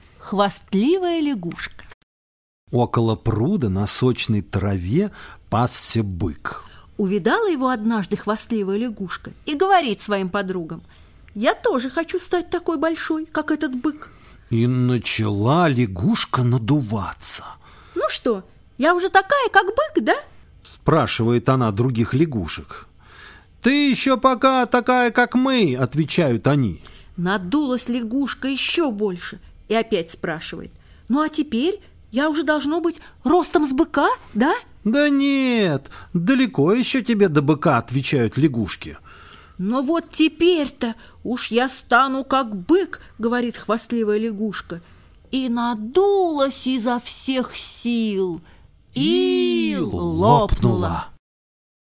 Хвастливая лягушка - латышская аудиосказка. Однажды увидела хвастливая лягушка быка и захотела стать такой же большой.